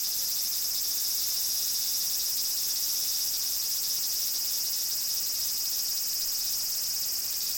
cicadas_day_loop_03.wav